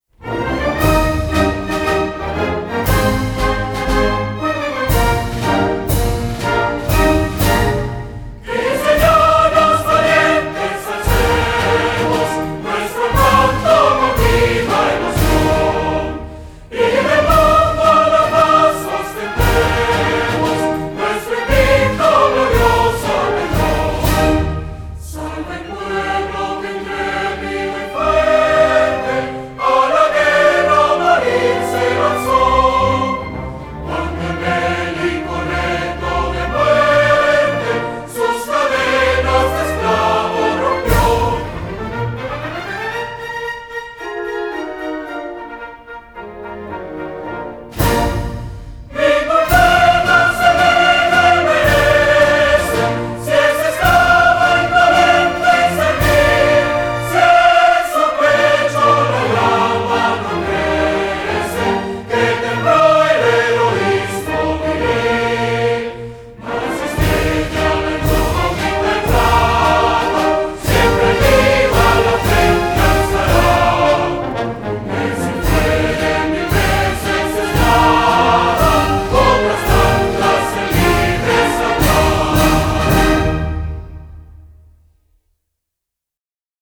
HIMNO